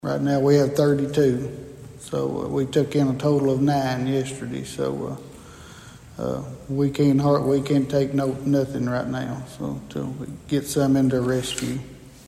The Princeton City Council’s meeting Monday night was brief but packed with positive updates, as department heads shared news of new hires, ongoing projects, and community events.